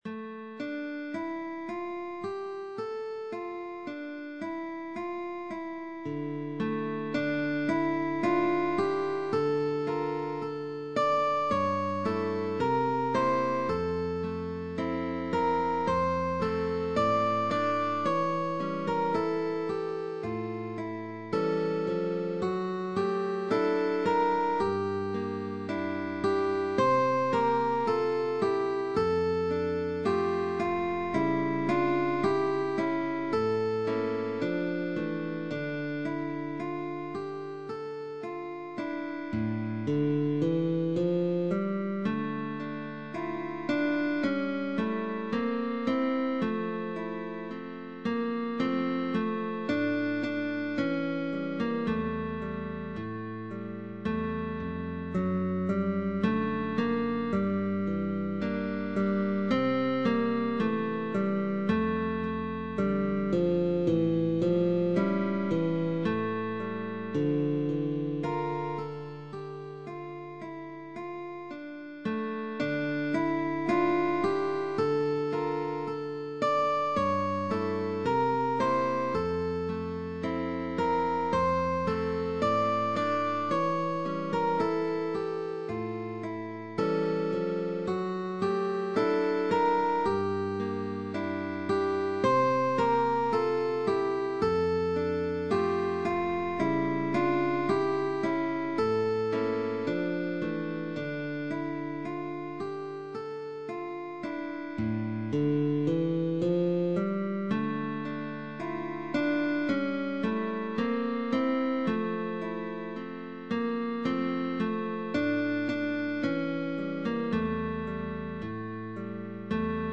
Guitar duo sheetmusic.
is a traditional Catalan Christmas song and lullaby.
GUITAR DUO : Pupil & Teacher